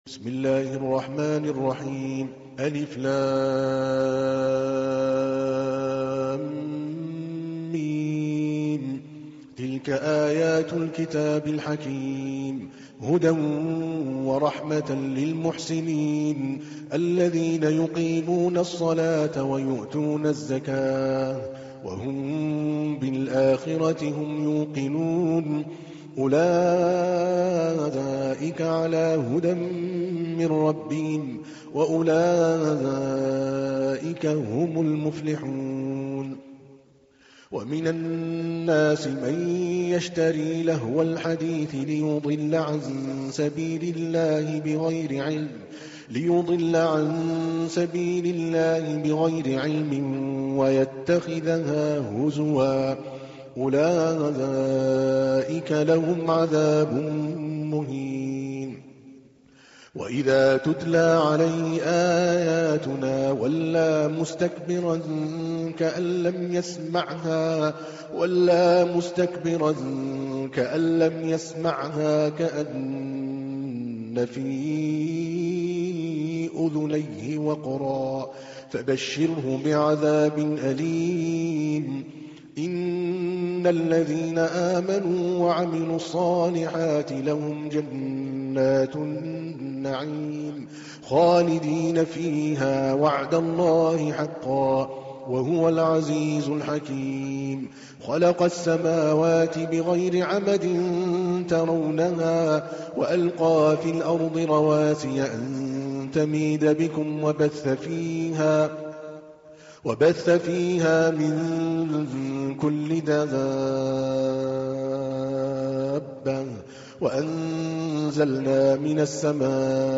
تحميل : 31. سورة لقمان / القارئ عادل الكلباني / القرآن الكريم / موقع يا حسين